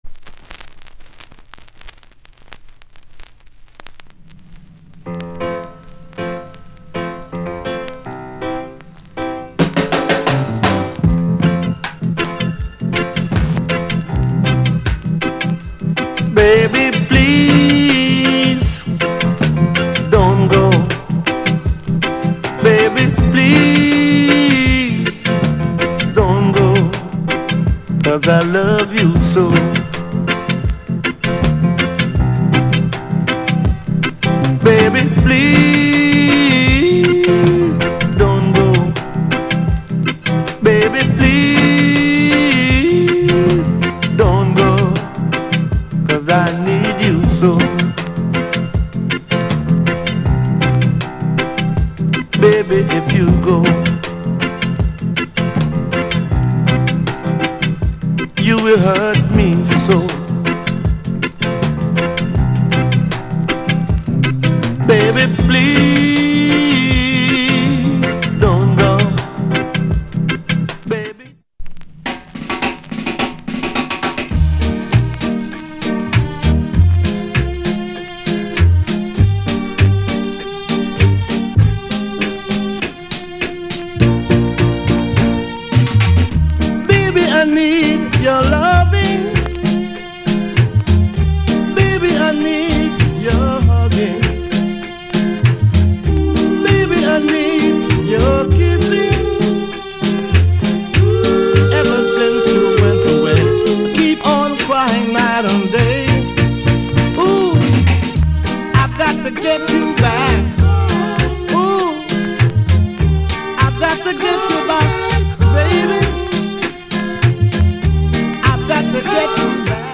* 1970 Reggay